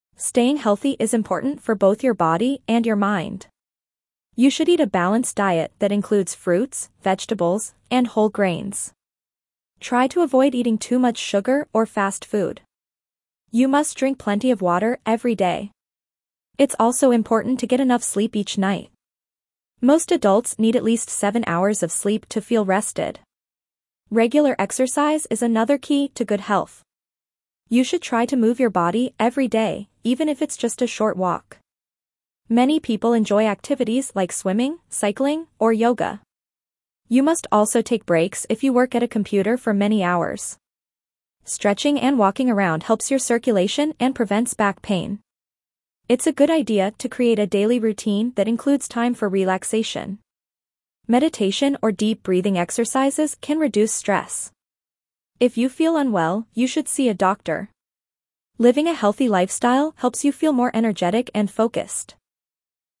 Dictation B1 - Healthy Habits
Your teacher will read the passage aloud.
3.-B1-Dictation-Healthy-Habits.mp3